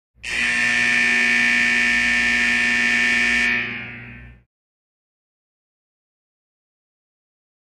Factory Buzzer, Reverberant, Interior.